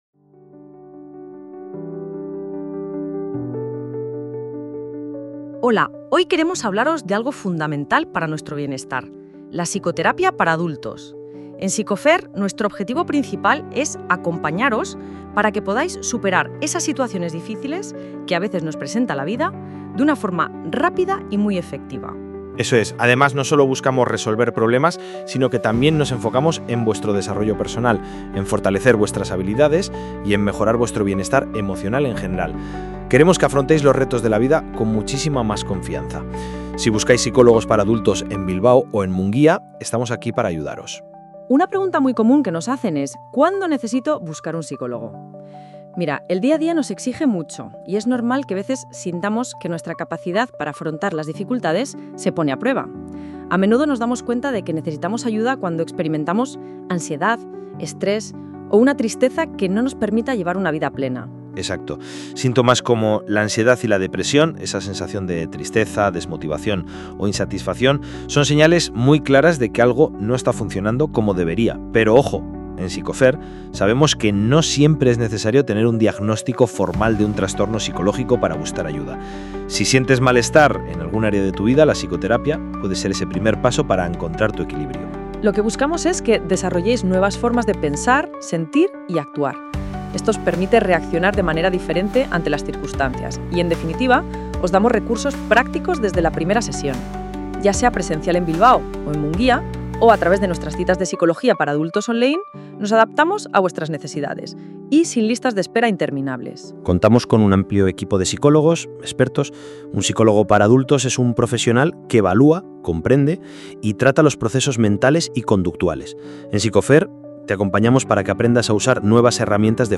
Si no te apetece leer, en este episodio de nuestro podcast te resumimos todo lo que necesitas saber sobre nuestro enfoque de terapia infantil en una conversación clara y cercana.